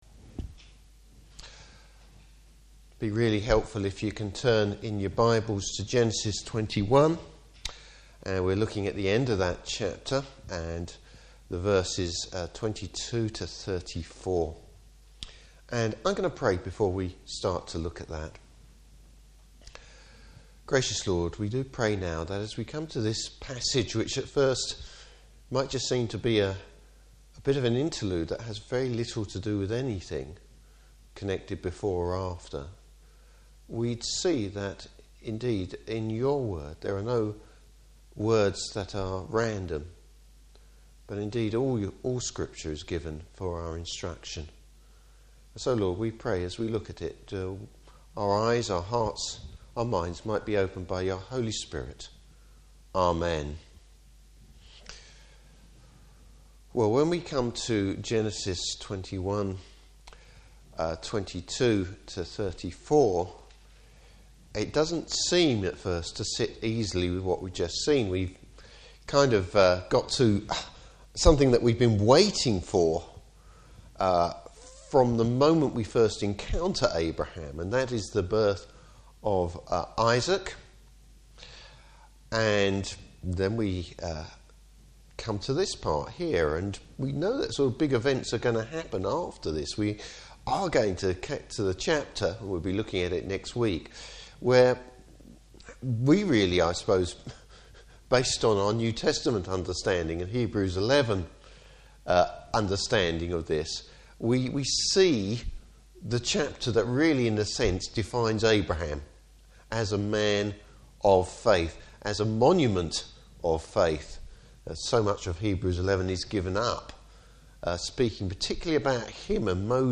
Service Type: Evening Service Abraham’s emerging faith.